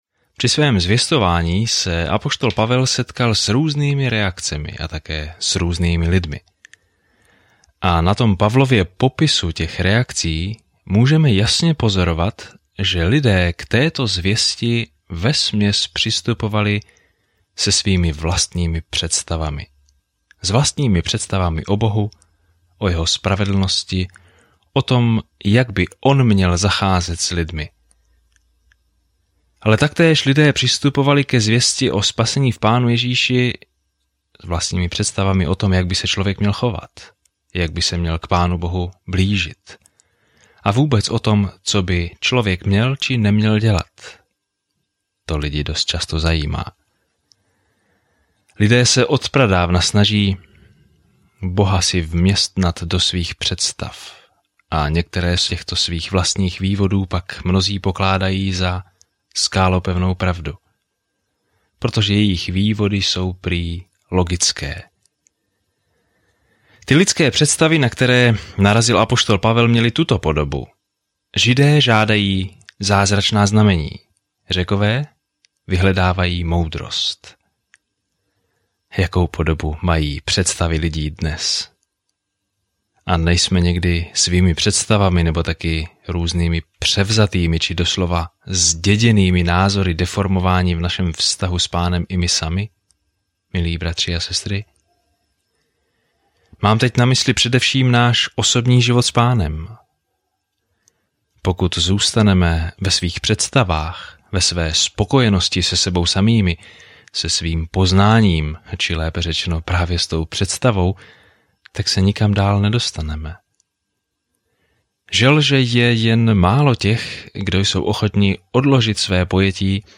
Denně procházejte 1. listem Korinťanům, zatímco budete poslouchat audiostudii a číst vybrané verše z Božího slova.